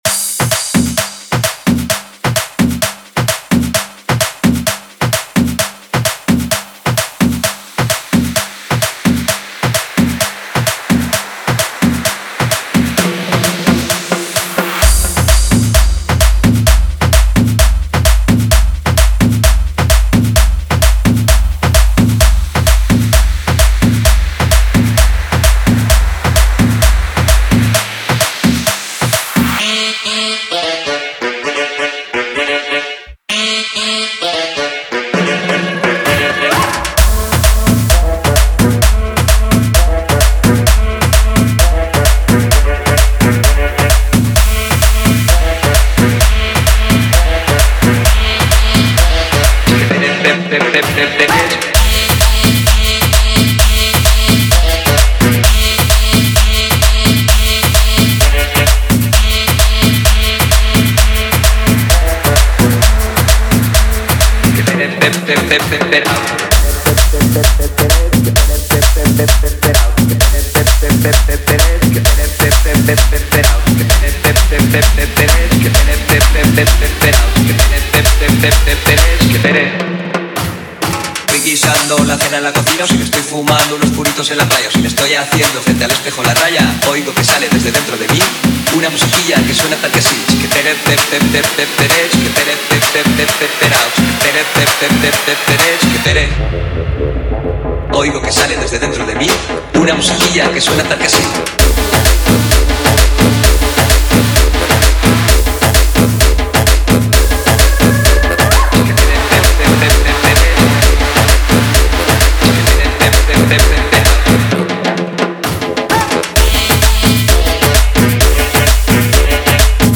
Reggaeton
DJ